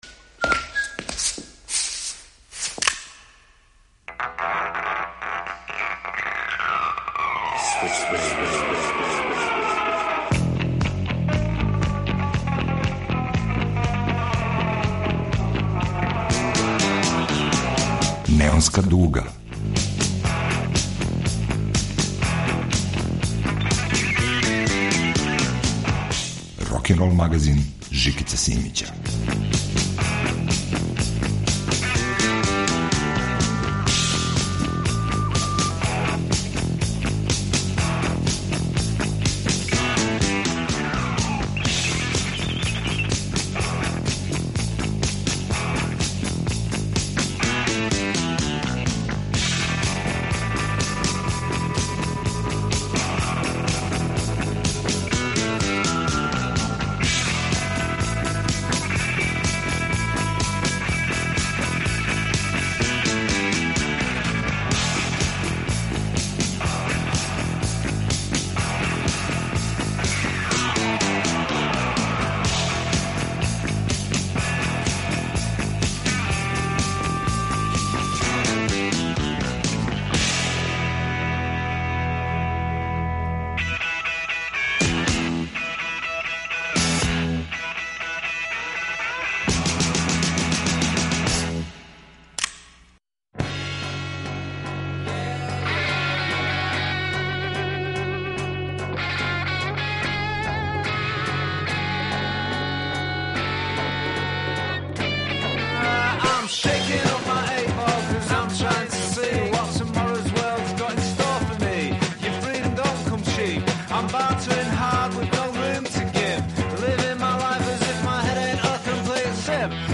На репертоару је више од двадесет песама у вратоломној авантури кроз жанрове и временске периоде.